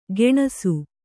♪ geṇasu